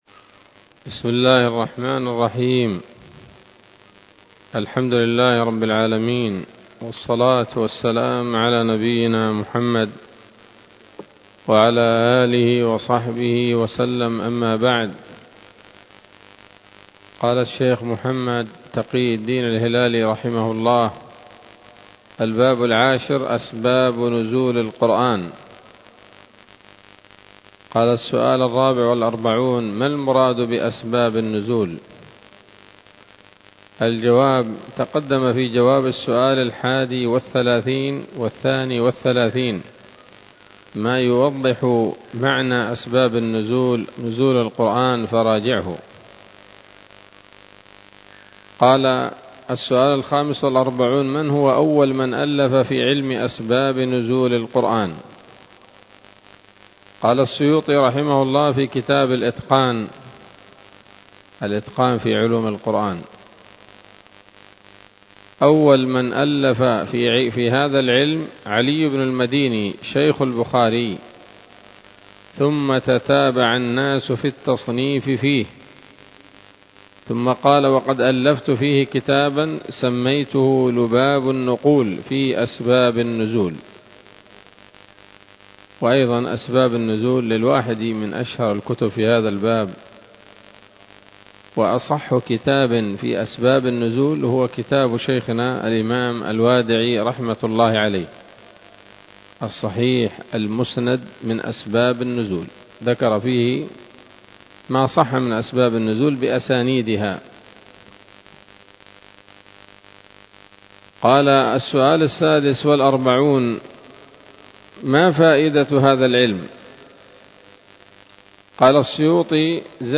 الدرس الخامس عشر من كتاب نبذة من علوم القرآن لـ محمد تقي الدين الهلالي رحمه الله